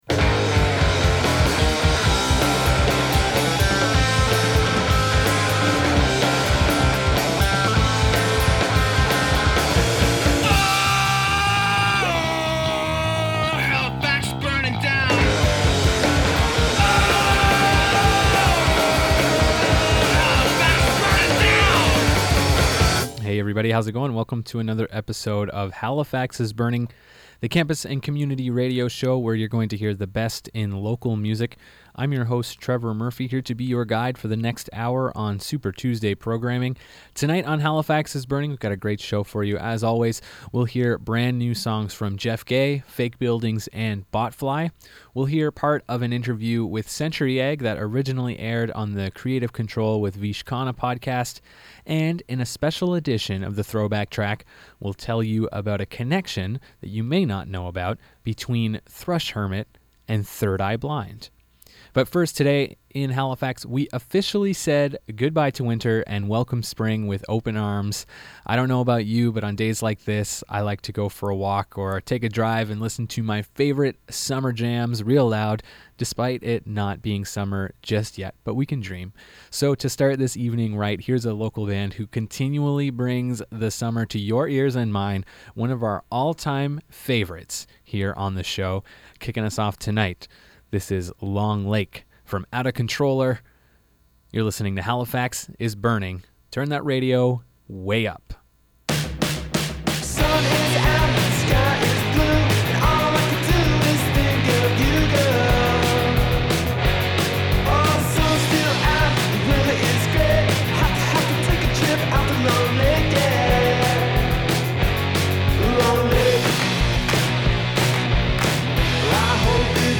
The best independent East Coast music